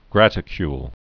(grătĭ-kyl)